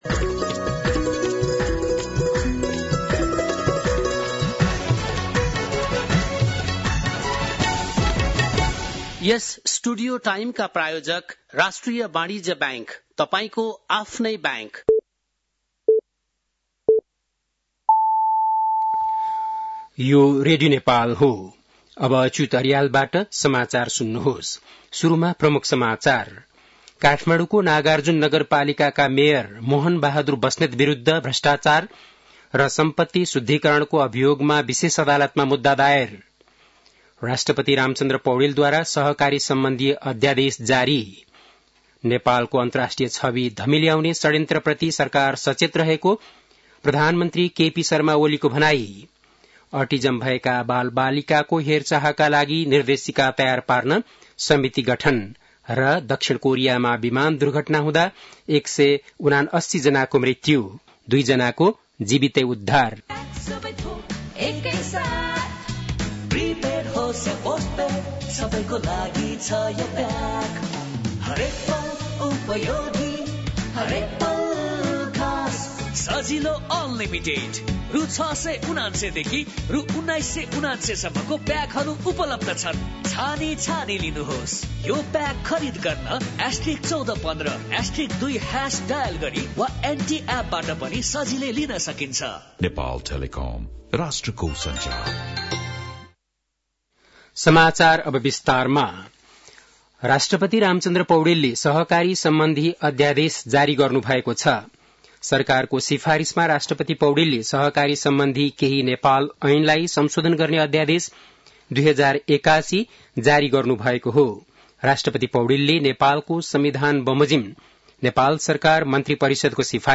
बेलुकी ७ बजेको नेपाली समाचार : १५ पुष , २०८१
7-PM-Nepali-News-9-14.mp3